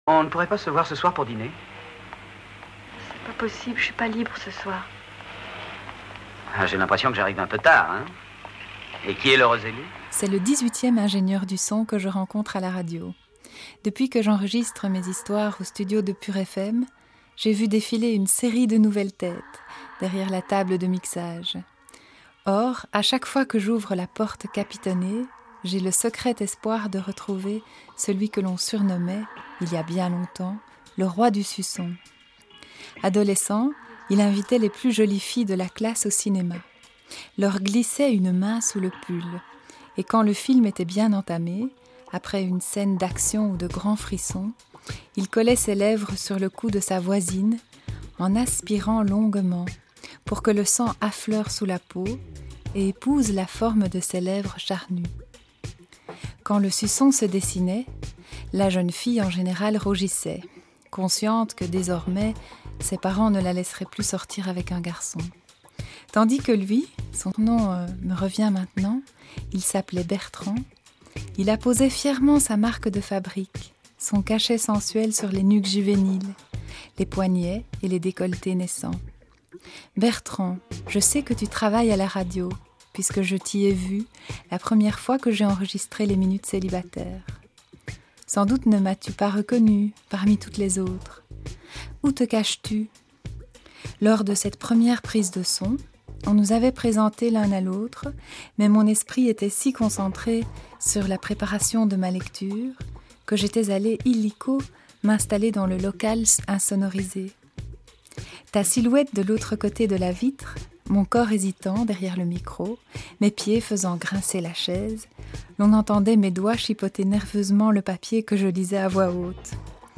Depuis que j’enregistre mes histoires au studio de Pure FM, j’ai vu défiler une série de nouvelles têtes,